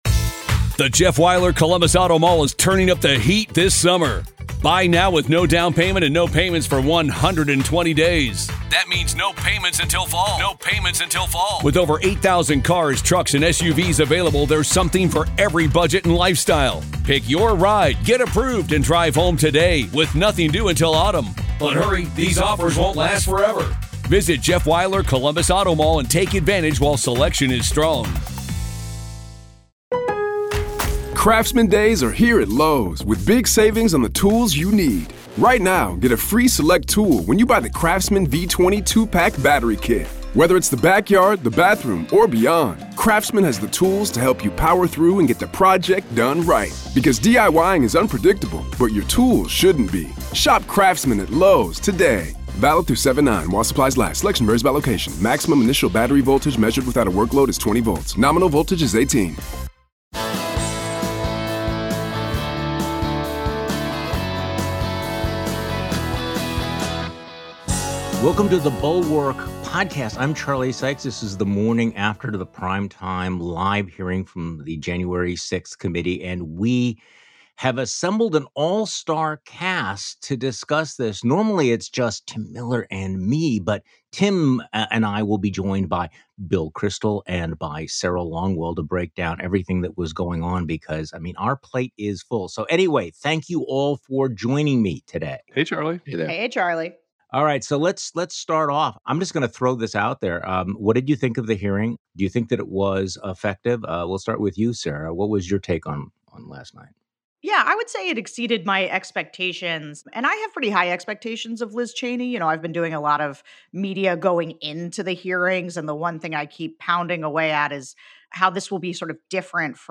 Liz Cheney and company rekindled our rage at Republicans: They knew Trump was lying, subverting the law, and inciting the mob — and 147 of them went along anyway. Bulwark all-stars Sarah Longwell, Tim Miller, and Bill Kristol join Charlie Sykes for the weekend podcast.